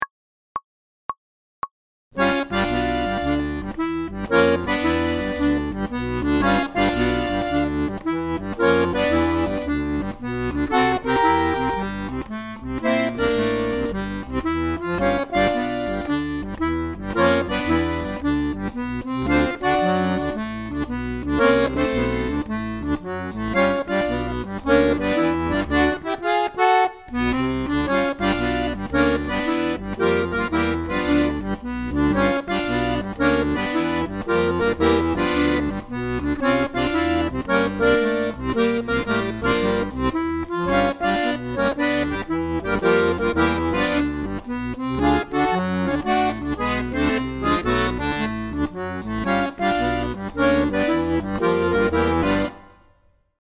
DIGITAL SHEET MUSIC - ACCORDION SOLO
Blues Compositions